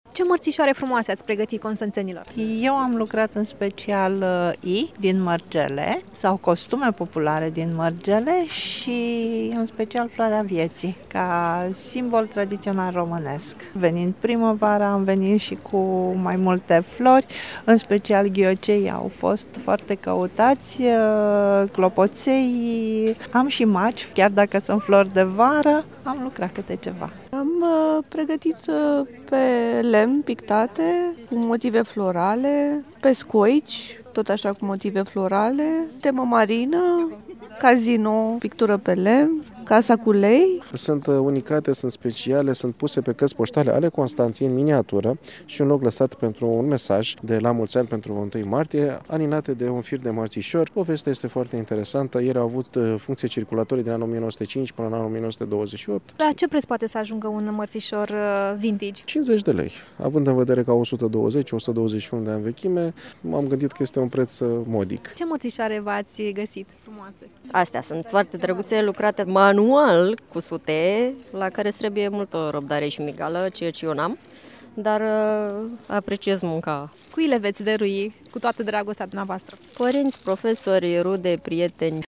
a vorbit cu expozanții, dar și cu vizitatorii Târgului